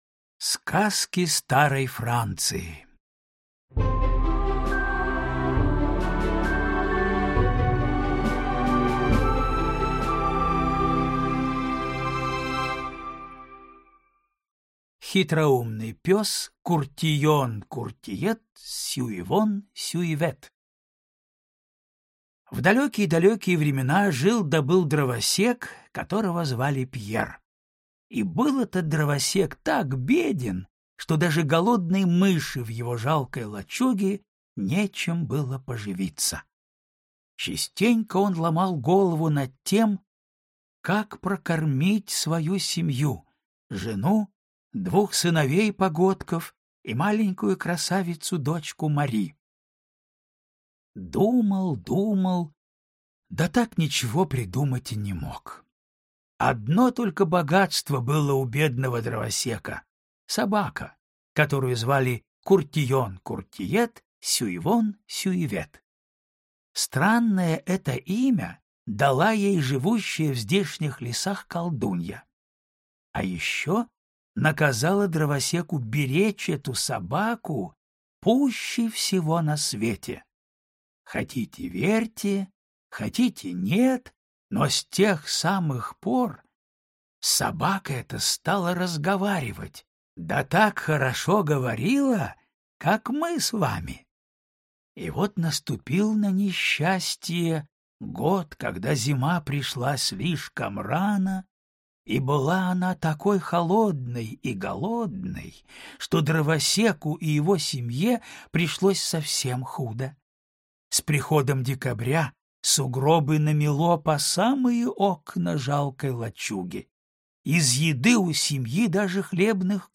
Аудиокнига Сказки старой Франции | Библиотека аудиокниг